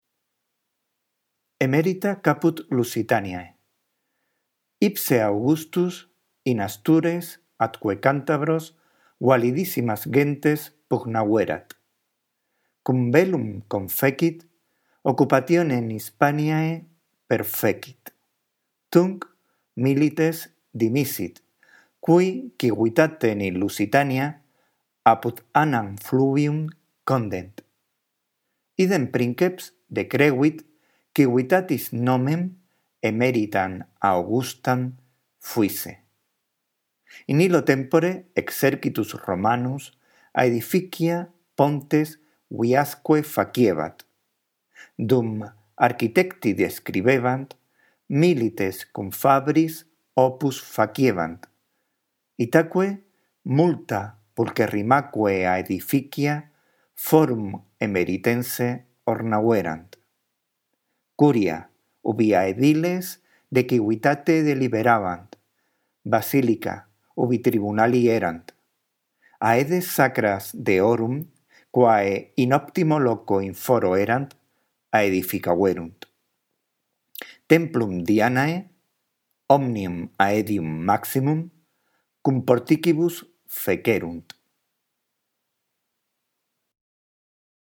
Tienes una lectura justo debajo del texto por si la necesitas.